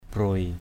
/brʊoɪ/ (cv.) buai =b& (t.) tàn, héo = près de s’éteindre, desséché, flétri. hala kayau bruai abih hl% ky~@ =b& ab{H lá cây tàn héo hết.